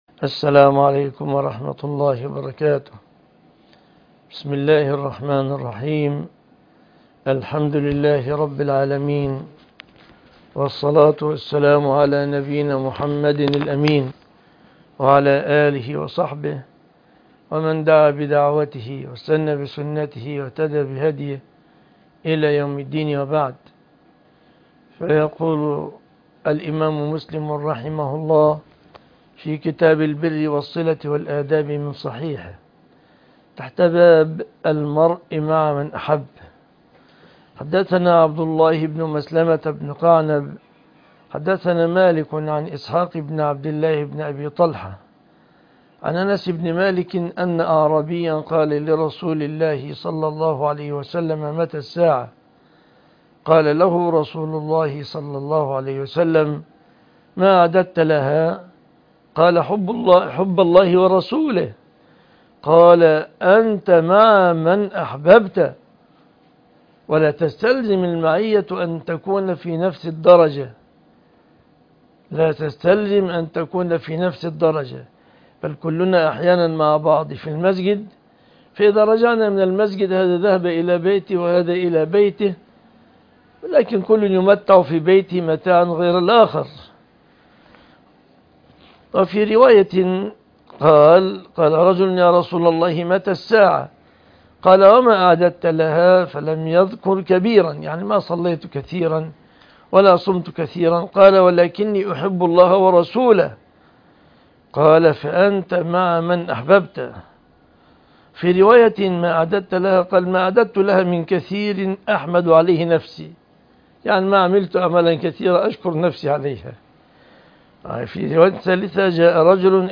الحديث وعلومه     شرح الأحاديث وبيان فقهها